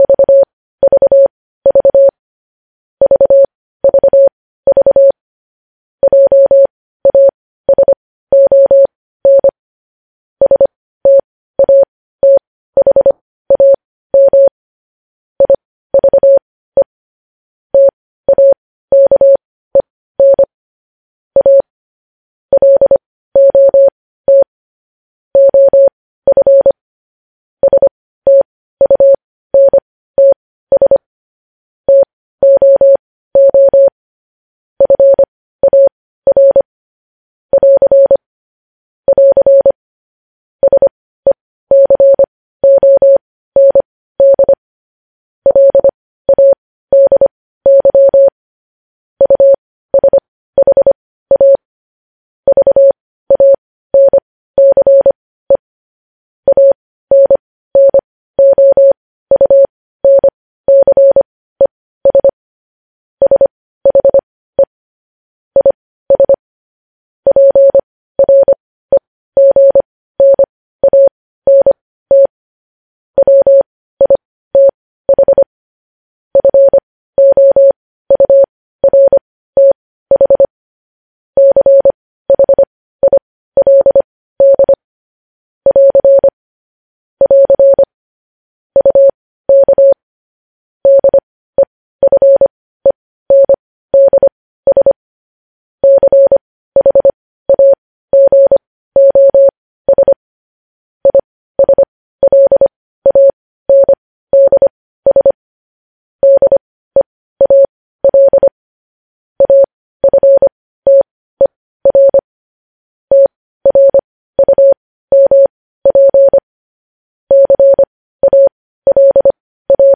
News Headlines in Morse Code at 15 WPM
News Headlines in Morse code, updated daily